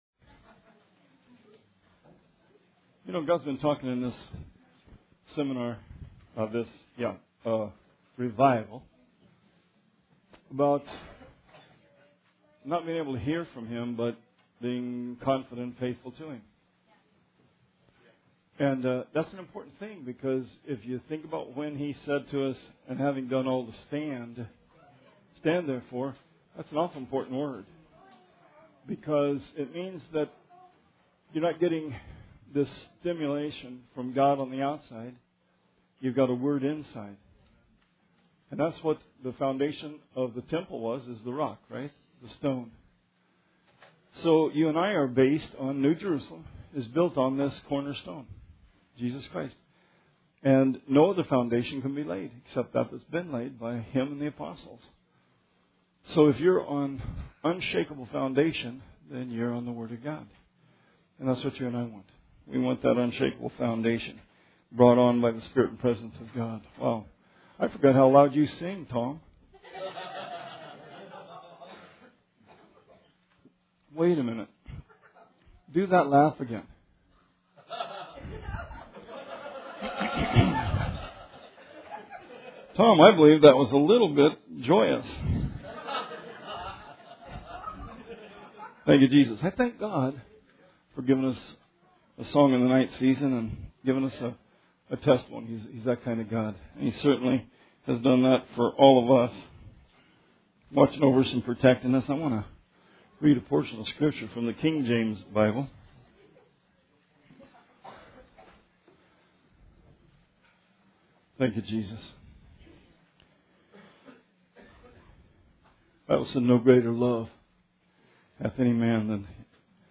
Sermon 12/31/16